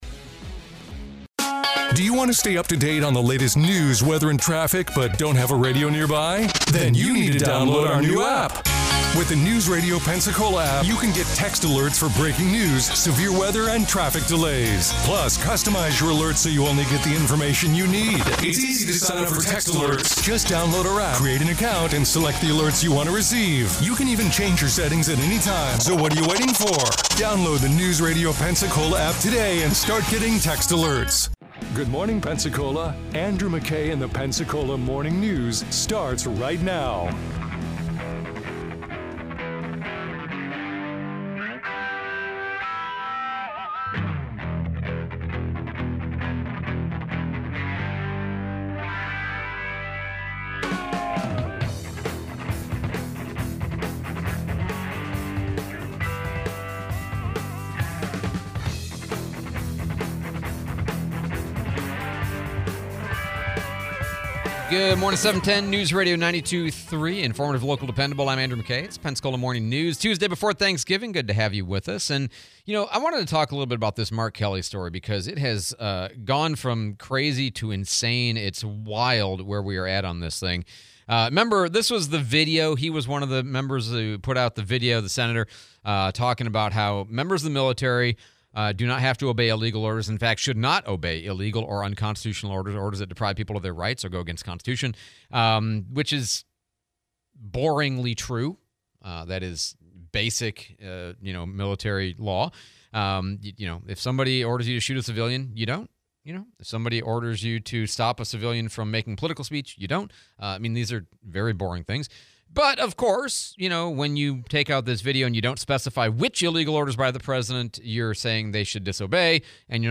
AZ US Senator Mark Kelly, interview with Sheriff Chip Simmons